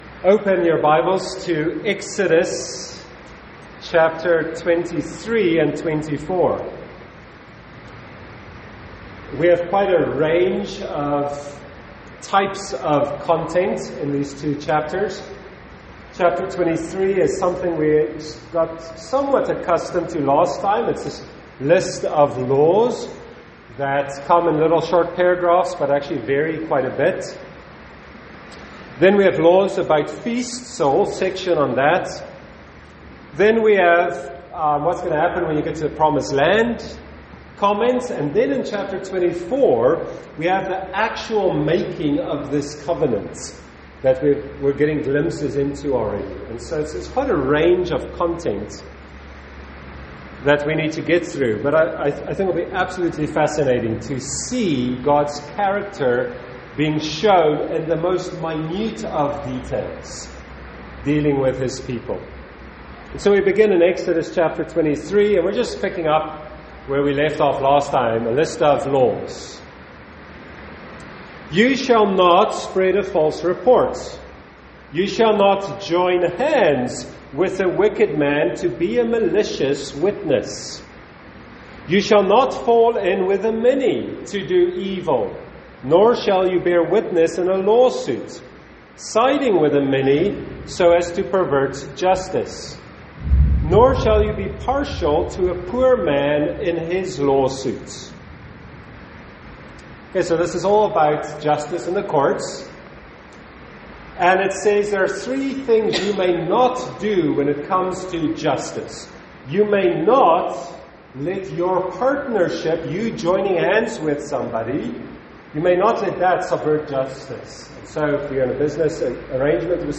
Note: We apologise, there is a bit of background noise in this recording due to bad weather.